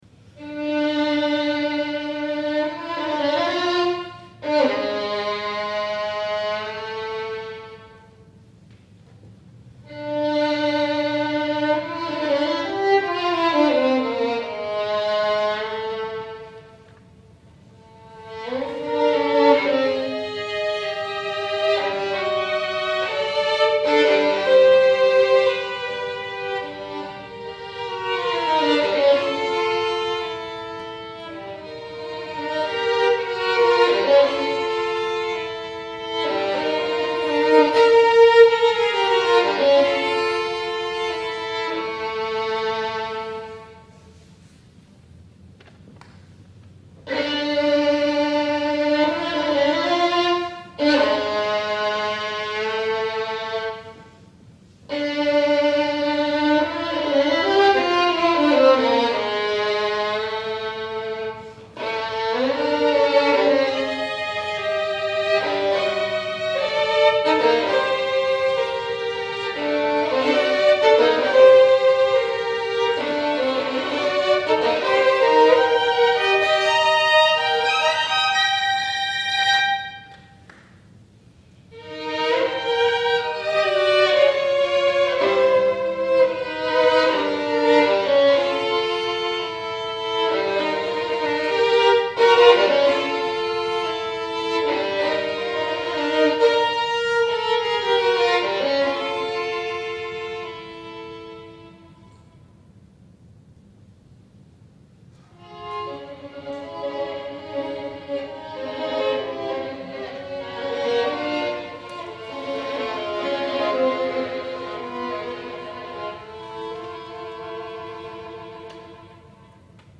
Recitative
Fritz Kreisler - Recitativo and Scherzo Caprice Op. 6 for solo violin